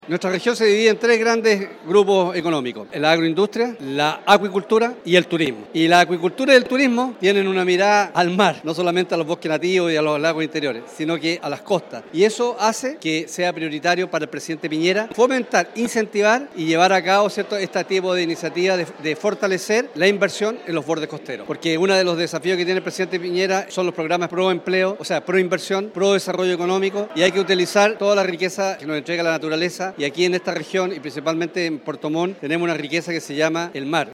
En conversación con el diputado Alejandro Santana, se confirmó la visita del Mandatario Sebastián Piñera a la región de Los Lagos, en la cual, según el parlamentario se podrían abordar temas importantes relacionados a la agroindustria, la acuicultura y el turismo.